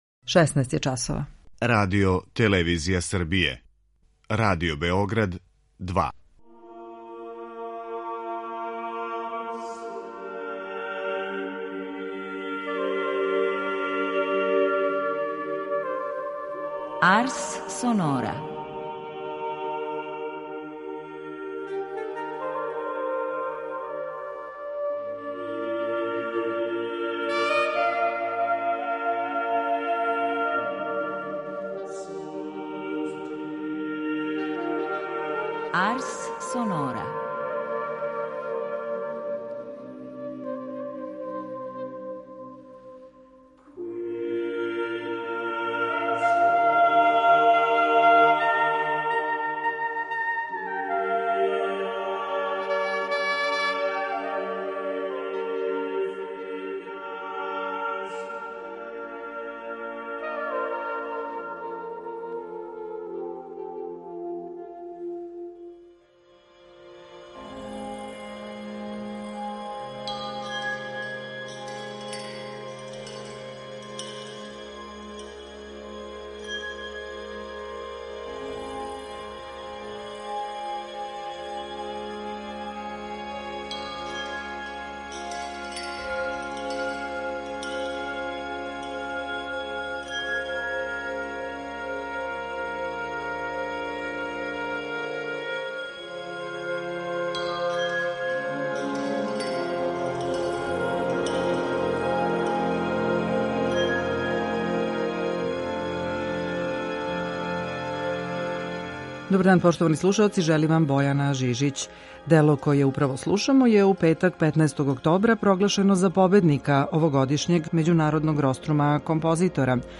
Рубрика „Јубилеји и годишњице" овога пута посвећена је Францу Листу и обележавању 210 година од његовог рођења. Из Листовог опуса издвајамо клавирске комаде из његовог циклуса „Године ходочашћа".